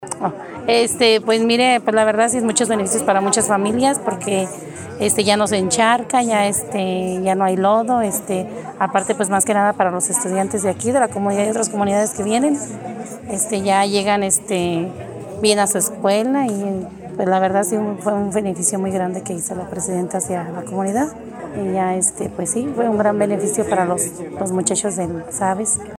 Lorena Alfaro García – Presidenta Municipal